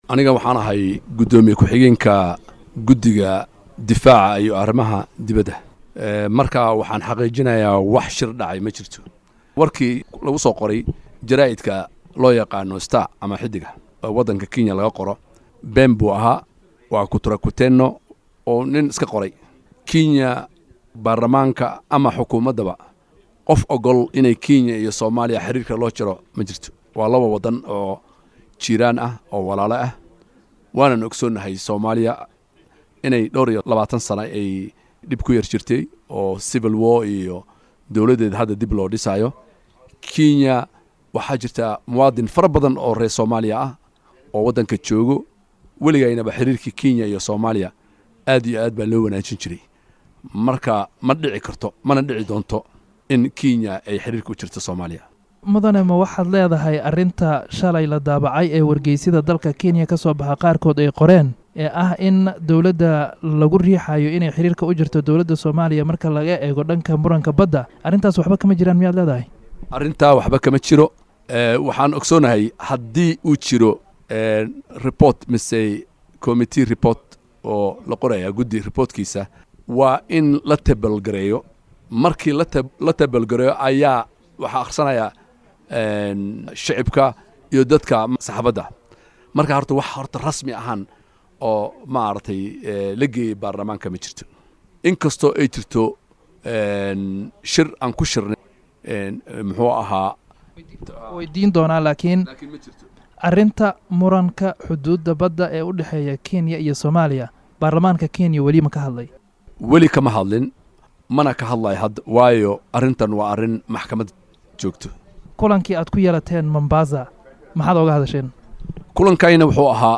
WAREYSI, Xil_Ilyaas_Barre_Shiil_HOL.mp2